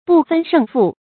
讀音讀法：
不分勝負的讀法